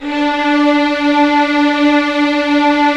Index of /90_sSampleCDs/Roland - LA Composer vol. 4/STR_TIDE 01/STR_'Violins _